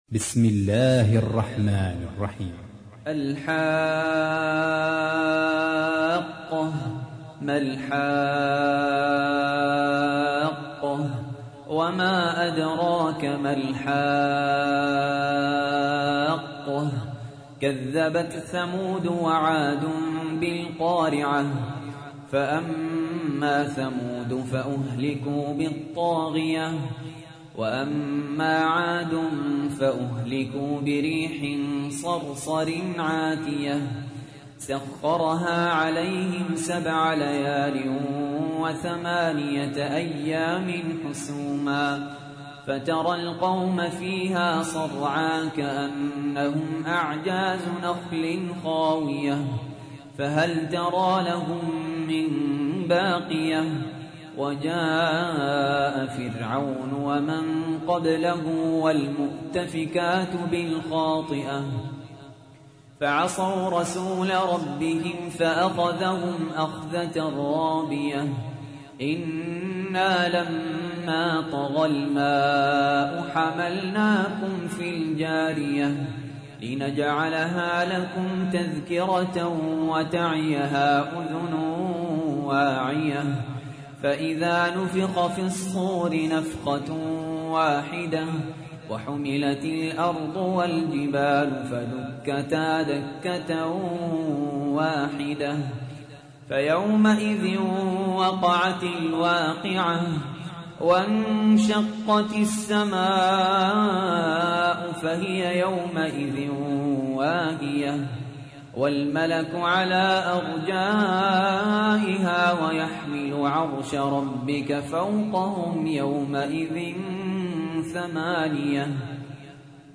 تحميل : 69. سورة الحاقة / القارئ سهل ياسين / القرآن الكريم / موقع يا حسين